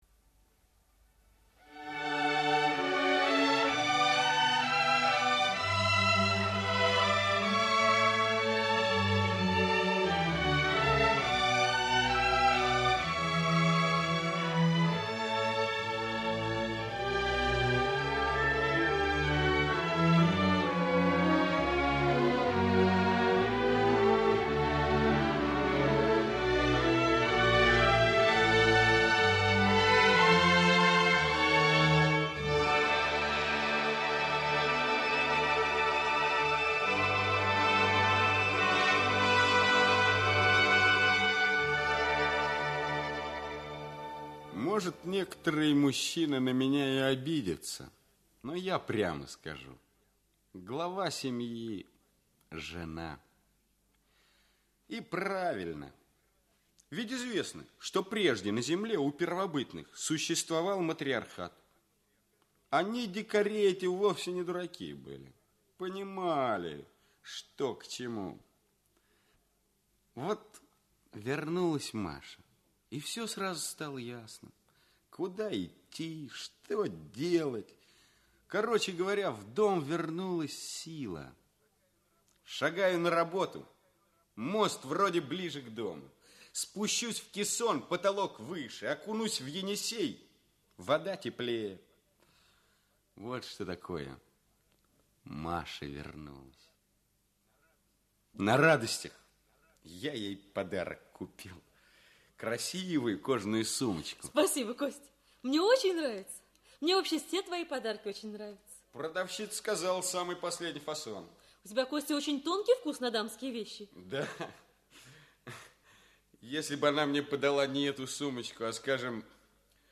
Аудиокнига Не отдавай королеву. Часть 2 | Библиотека аудиокниг
Часть 2 Автор Сергей Сартаков Читает аудиокнигу Олег Табаков.